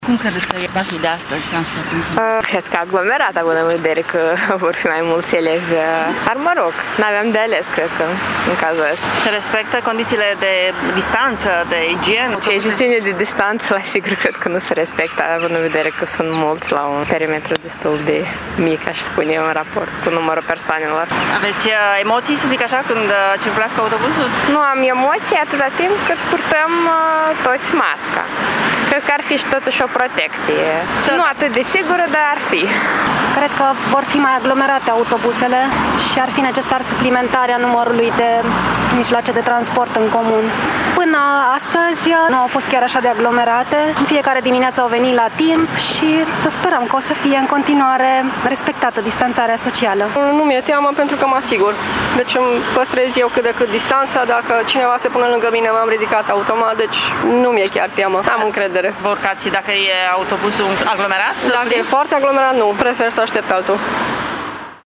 Târgumureșenii care circulă cu transportul în comun spun că se așteptau ca de astăzi autobuzele să fie aglomerate, însă depinde de fiecare cetățean ca regulile să fie respectate: